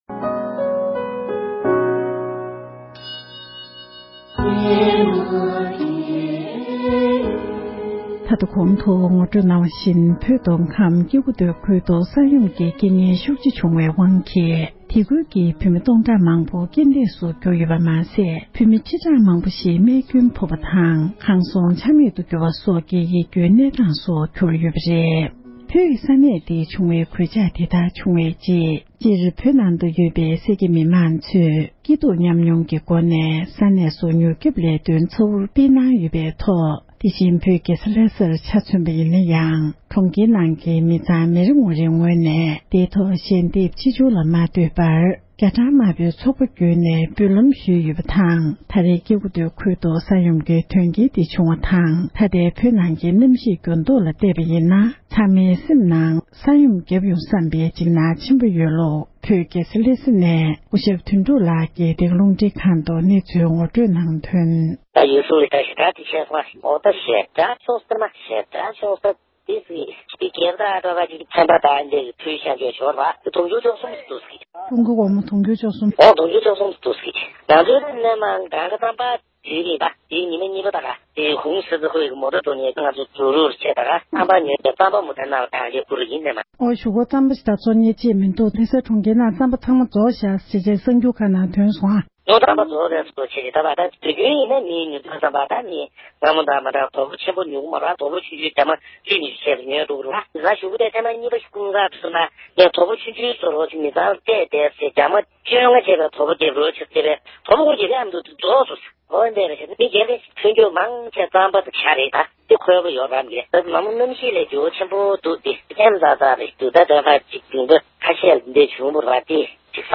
འབྲེལ་ཡོད་མི་སྣར་གནས་འདྲི་ཞུས་ཏེ་ཕྱོགས་བསྒྲིགས་ཞུས་པར་གསན་རོགས༎